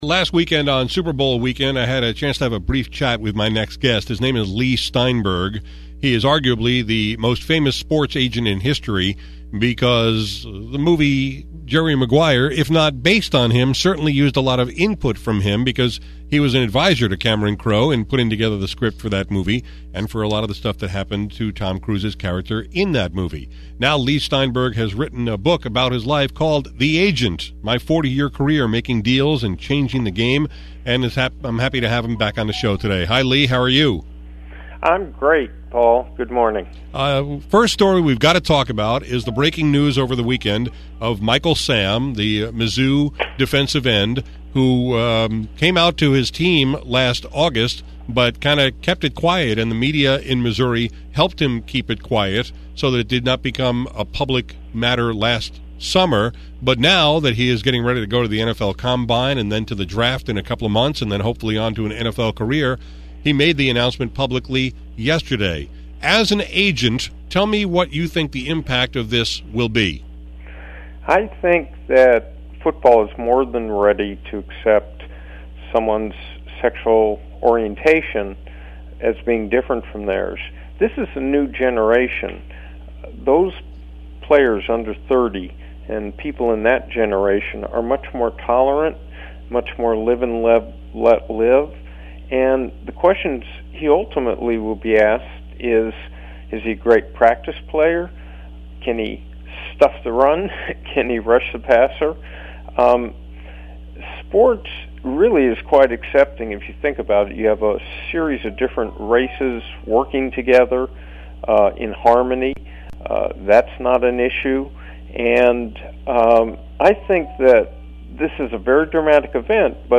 Leigh Steinberg, the veteran sports agent, was back on the air with me today to discuss the coming-out announcement by Michael Sam, the former Missouri Tiger who was SEC defensive player of the year and is about to be drafted by an NFL team. I asked Steinberg what impact the announcement will have on Sam’s chances in the draft and his future earning potential.